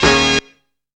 MADNESS STAB.wav